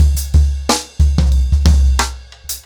Expositioning-90BPM.19.wav